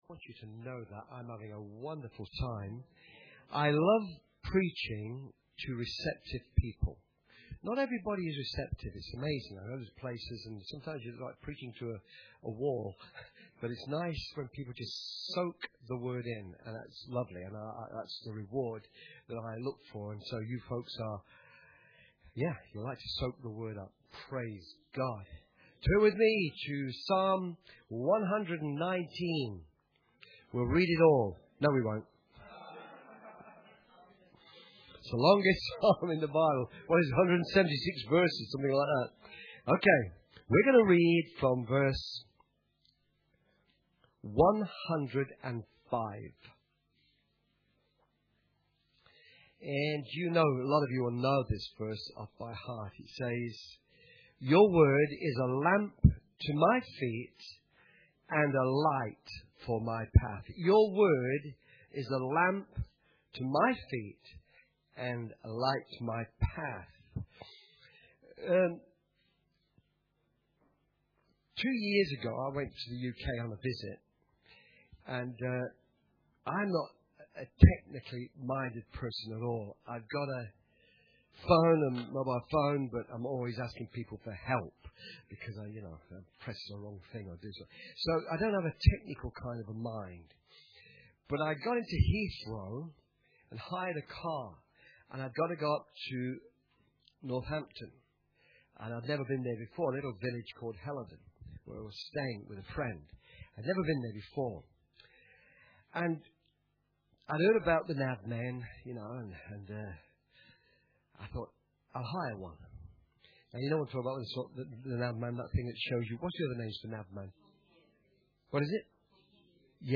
Church Camp 2014 - Saturday_Morn_Session2.mp3